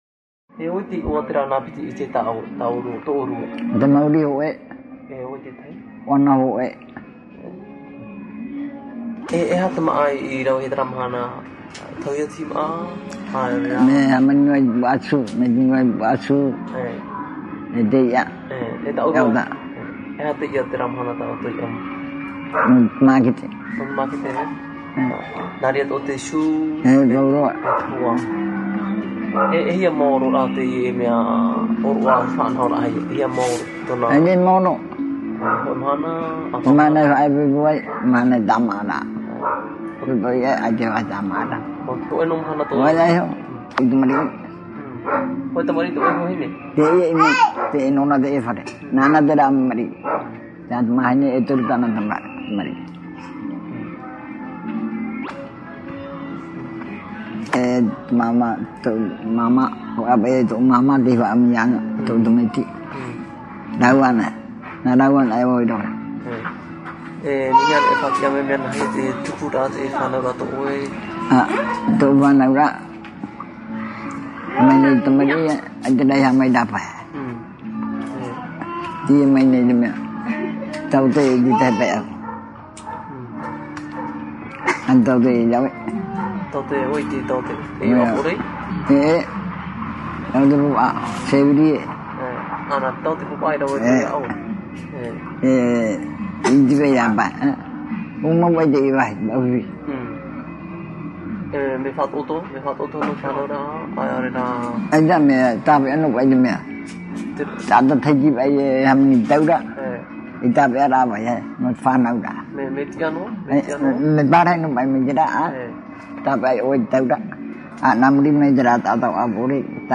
Te ta’ata fa’ati’a